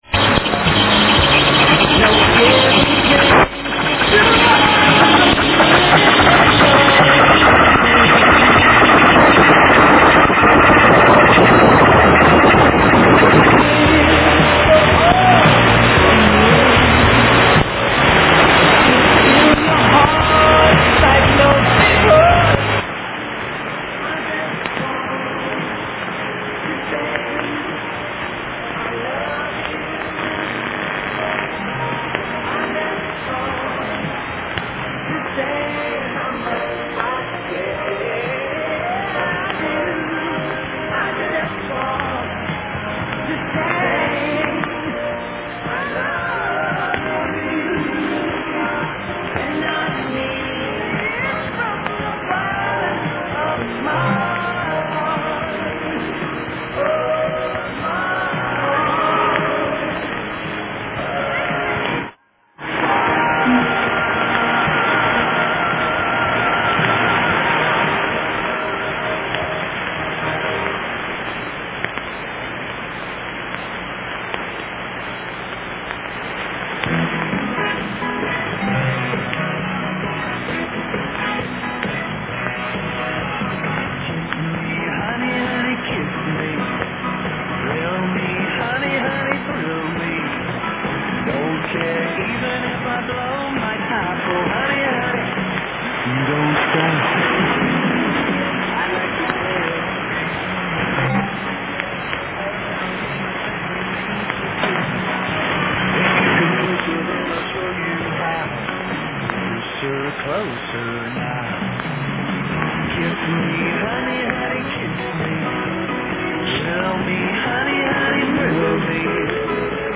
Er wird einmal gegen Ende angesagt. Ist ein Holländer……
UNID Pirate Station